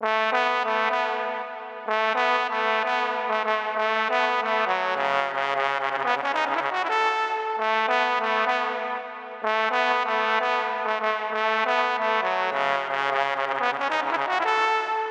trombone_saxo_court.wav